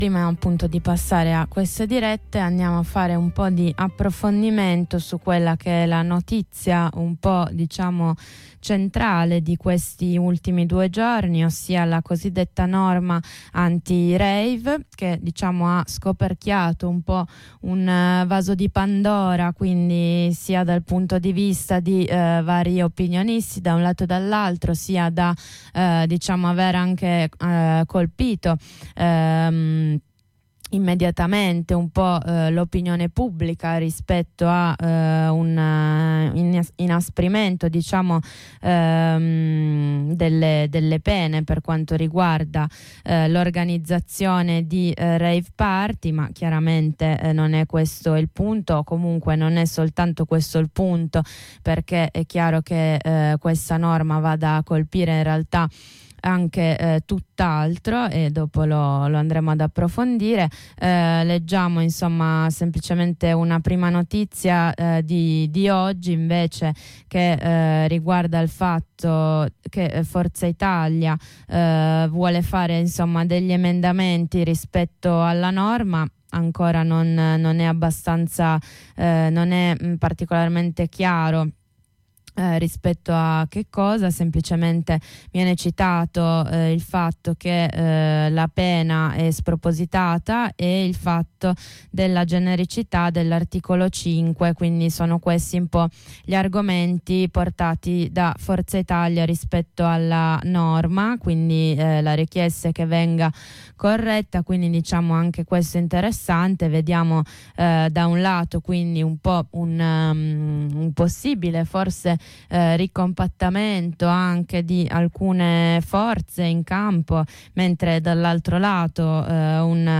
La due giorni di Non Una di Meno, come viene raccontato dalle compagne di Torino e Pisa rappresenta quindi un momento importante di confronto e di attivazione collettiva. La scelta di chiamare la manifestazione del 26 novembre contro la violenza di genere a Roma assume dunque un significato ancora più forte.